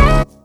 GUnit guitar.wav